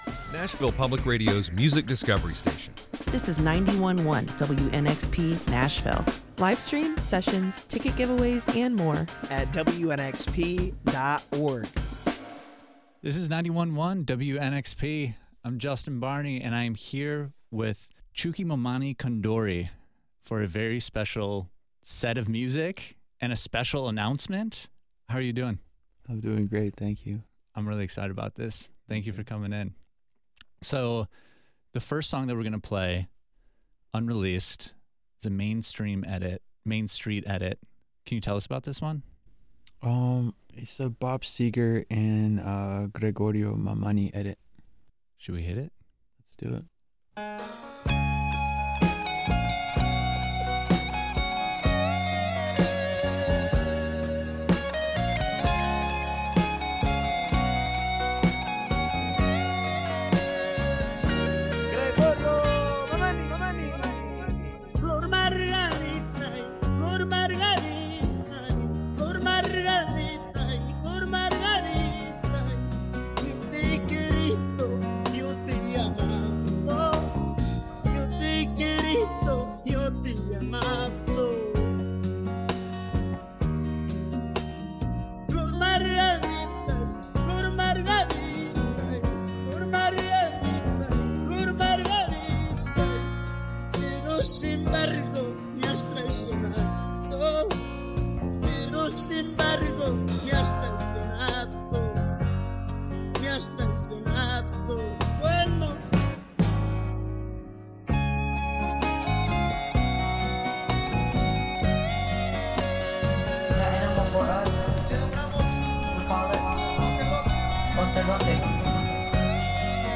You can listen back to the set in the audio above.